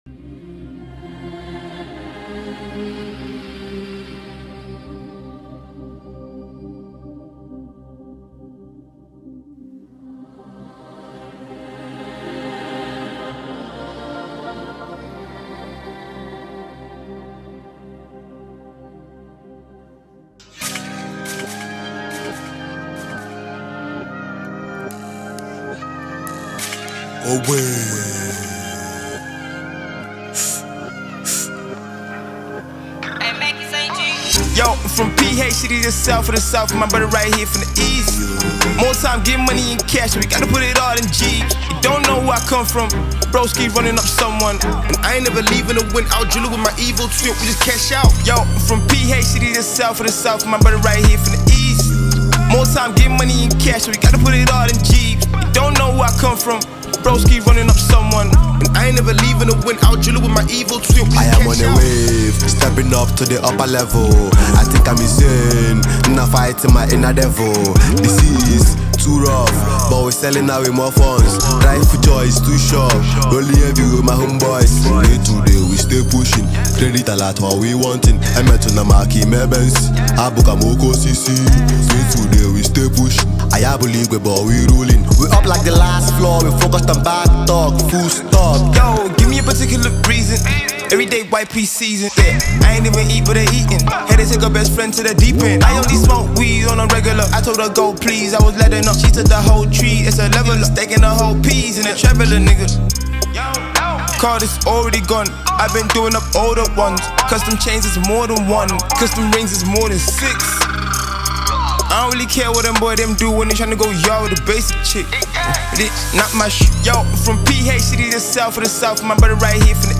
a Nigerian rapper, singer, and songwriter